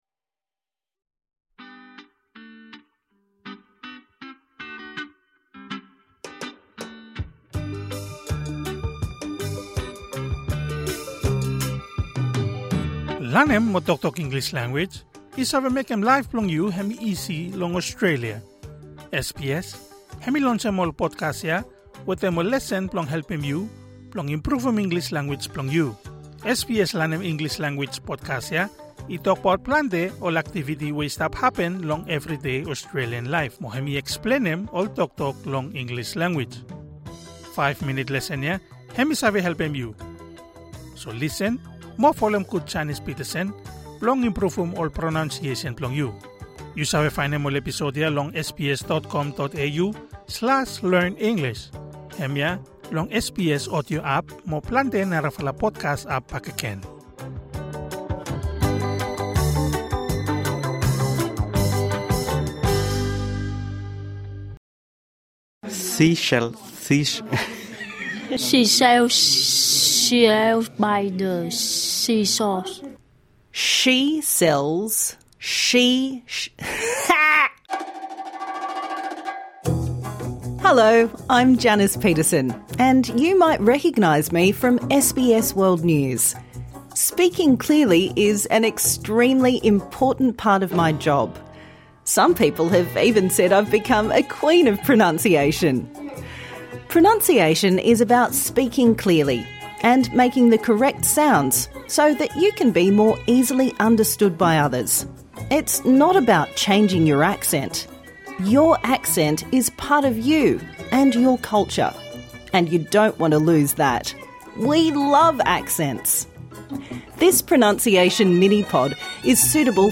MiniPod: S vs SH Praktisim Pronunciation